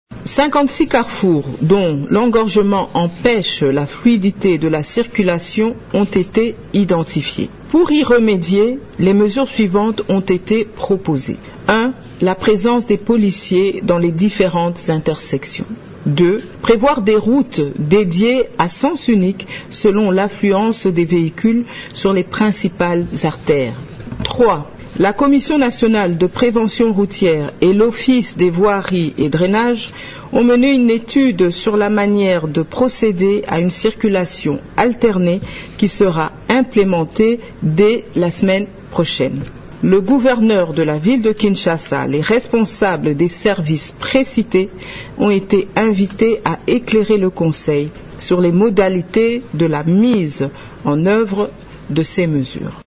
Selon le compte-rendu du conseil des ministres, lu par la ministre de la Culture et Arts, Yolande Elebe, ces mesures devraient entrer en vigueur à partir de cette semaine :